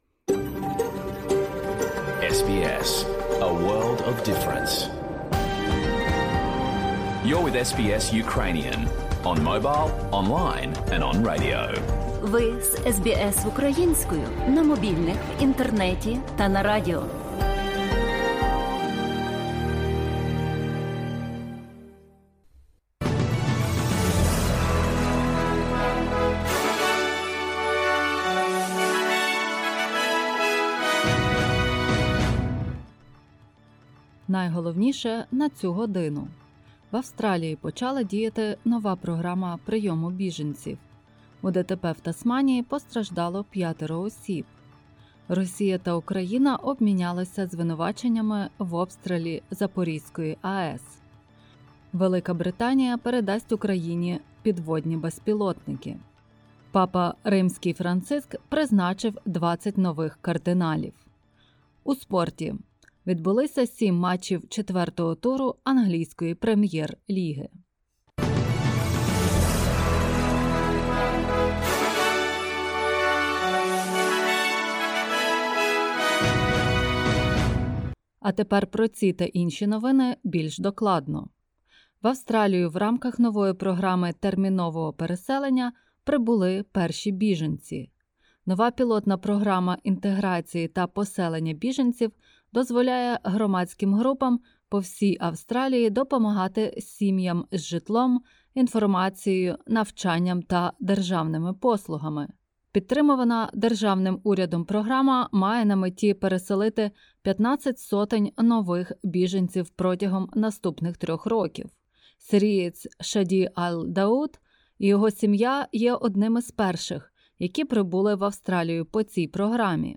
Бюлетень SBS новин українською мовою.